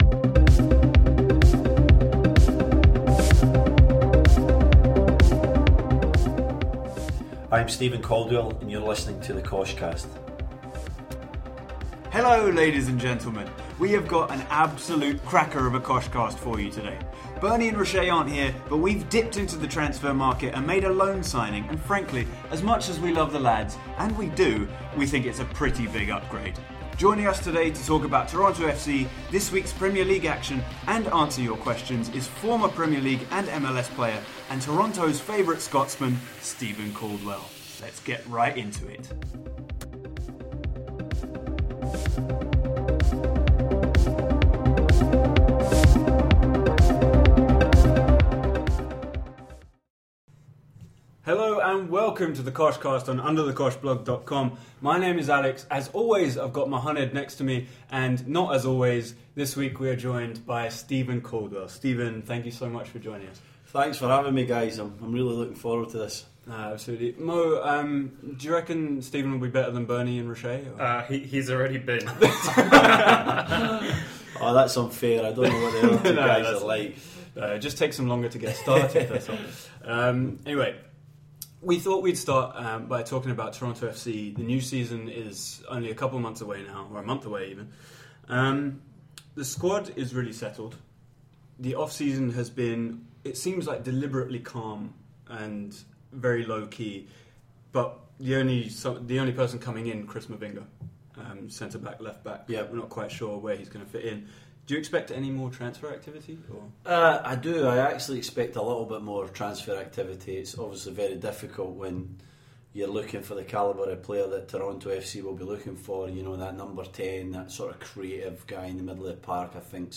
Joining us today to talk about Toronto FC, this week's Premier League action and answer your questions is former Premier League and MLS player, and Toronto's favourite Scotsman, Steven Caldwell.